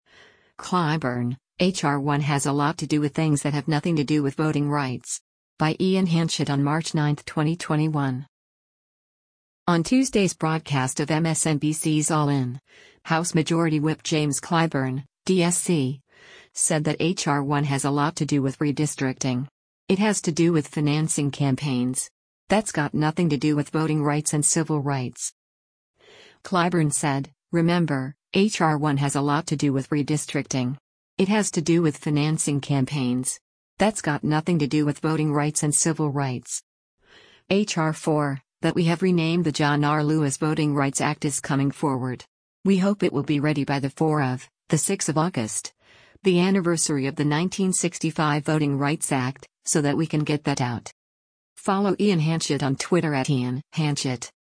On Tuesday’s broadcast of MSNBC’s “All In,” House Majority Whip James Clyburn (D-SC) said that H.R. 1 “has a lot to do with redistricting. It has to do with financing campaigns. That’s got nothing to do with voting rights and civil rights.”